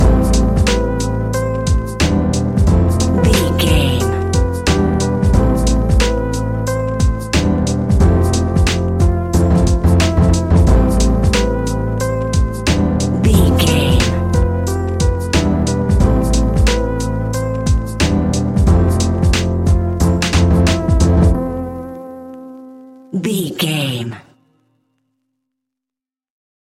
Ionian/Major
laid back
Lounge
sparse
chilled electronica
ambient